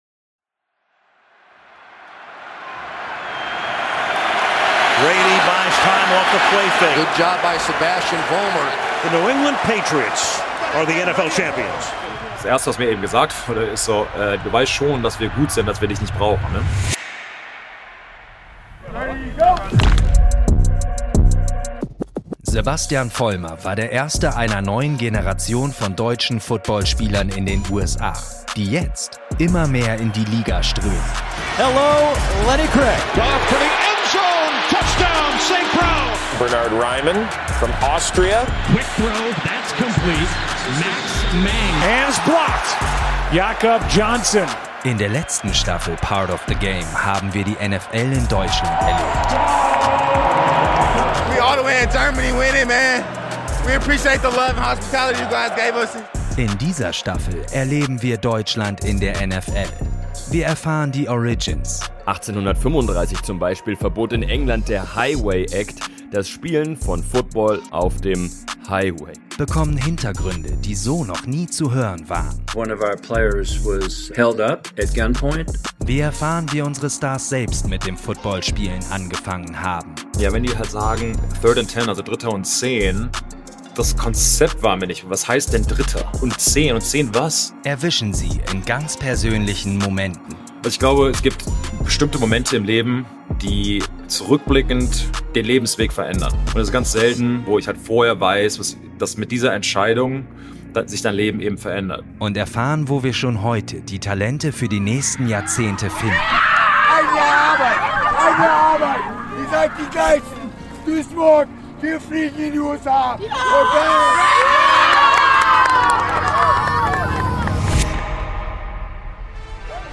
Wir sprechen mit Sebastian Vollmer, zweifacher Super Bowl Champion mit den New England Patriots und der bis heute erfolgreichste Deutsche in der National Football League. Sebastian erzählt uns von seinem langen Weg in die NFL.